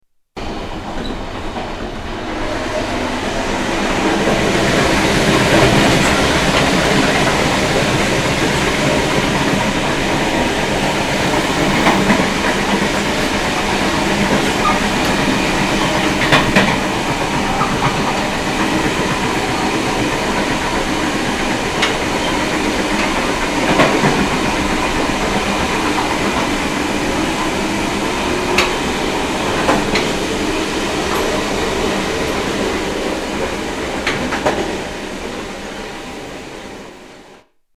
Cityrail Express train at Sydney